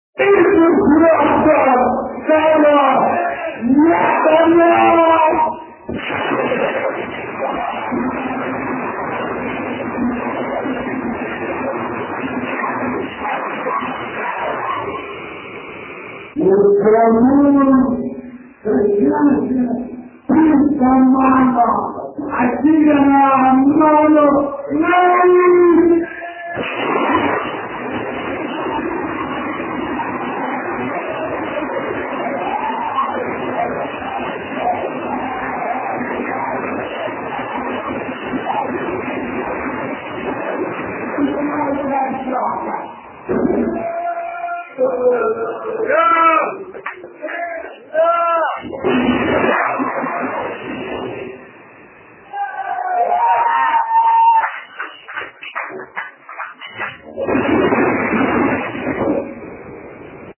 kurį nupiratavau koncerte 2004-12-19